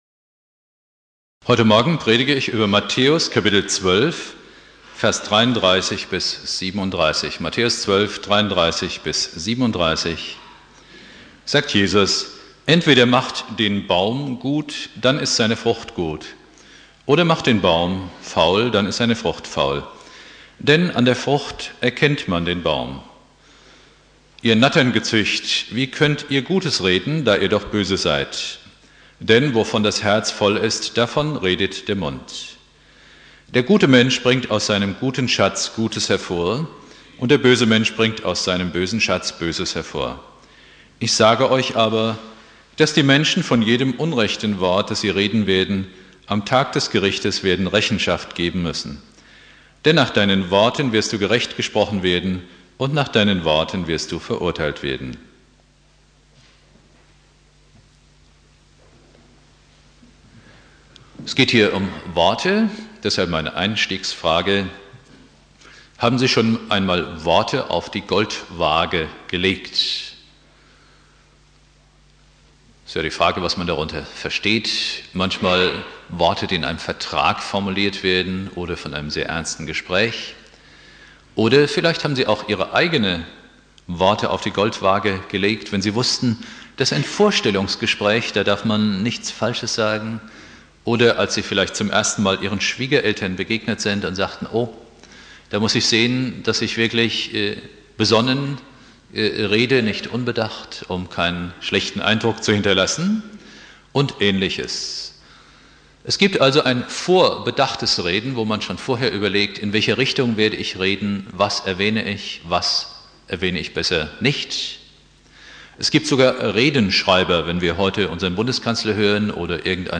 Predigt
Buß- und Bettag